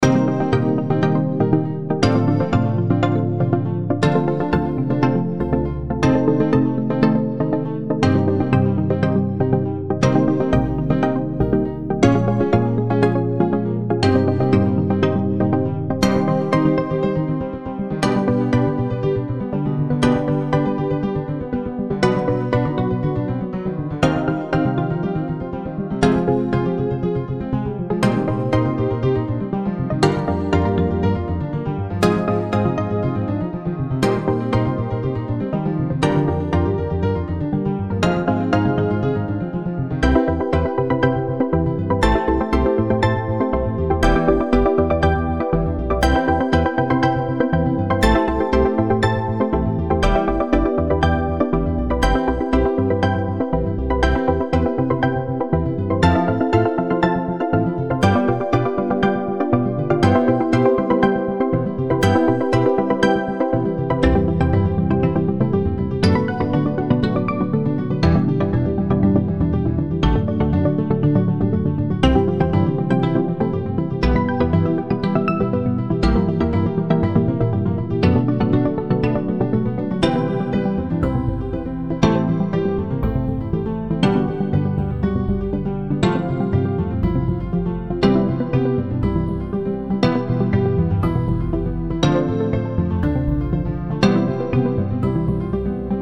デジプラック、ピアノ、アコースティックベース